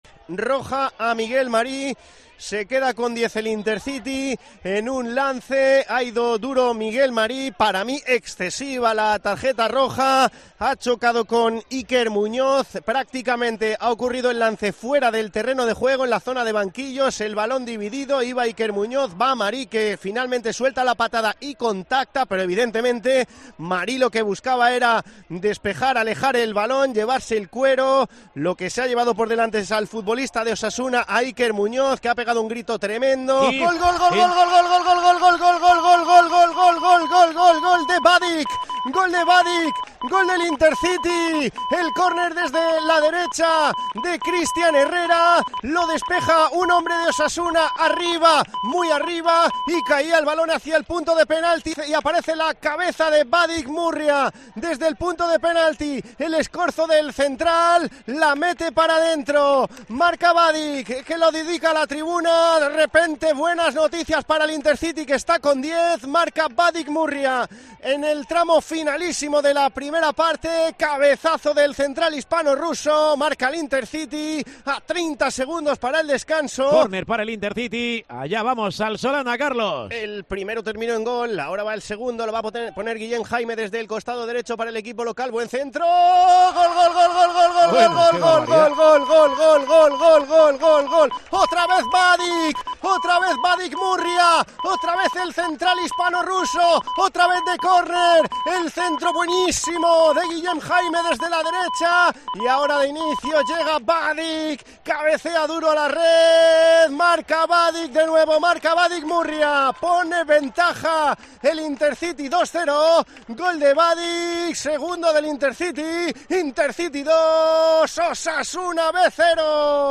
Sonidos del Intercity 2-1 Osasuna B